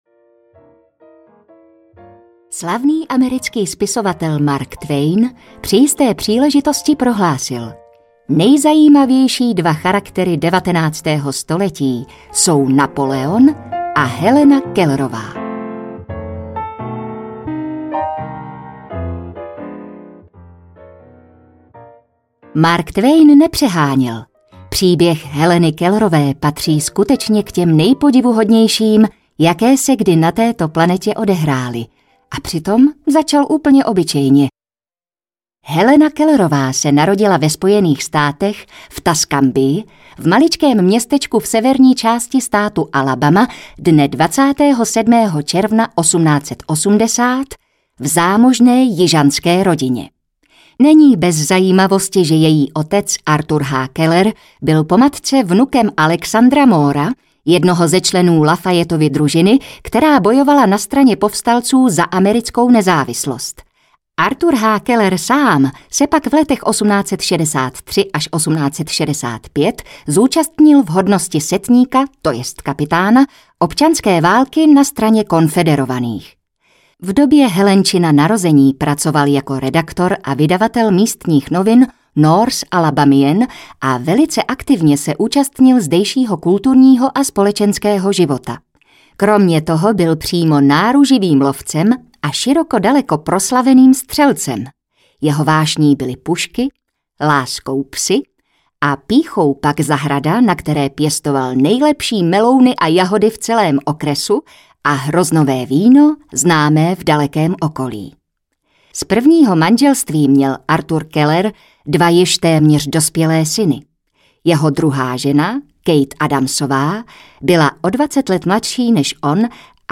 Via lucis audiokniha
Ukázka z knihy